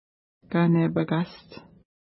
ID: 312 Longitude: -59.9460 Latitude: 53.4423 Pronunciation: ka:nepəka:st Translation: Point With Beautiful Leaf Trees Official Name: Brule Point Feature: point Explanation: There are birch trees along the point.